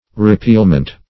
Repealment \Re*peal"ment\ (-ment), n.